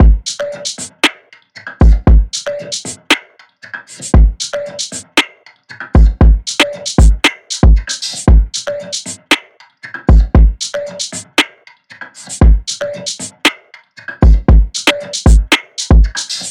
DRUM LOOPS
Time (116 BPM – Dm)
UNISON_DRUMLOOP_Time-116-BPM-Dm.mp3